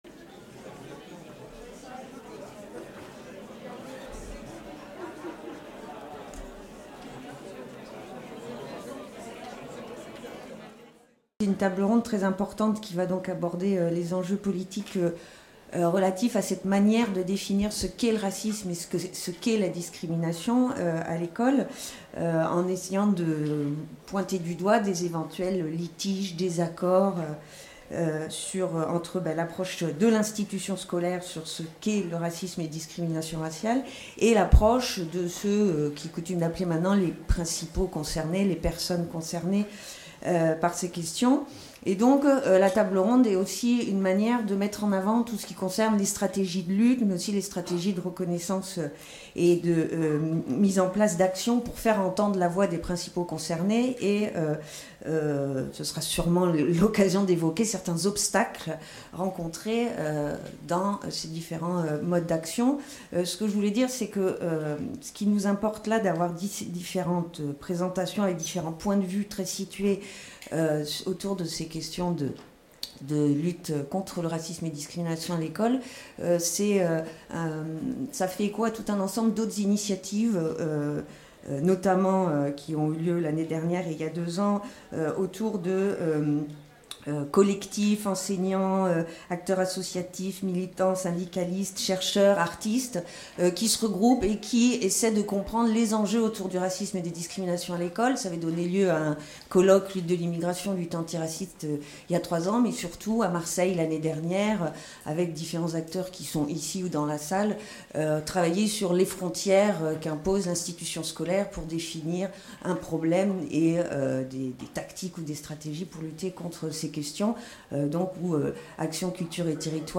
Table-ronde : Les luttes antiracistes et antidiscriminatoires dans et autour de l’école